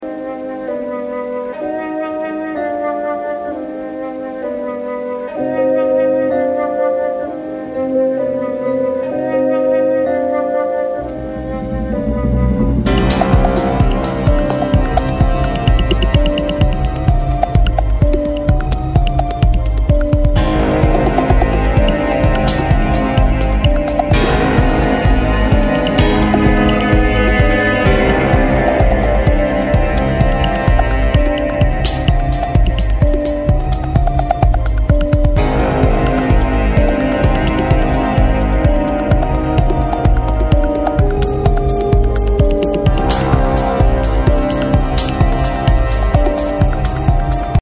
Electronix Techno Detroit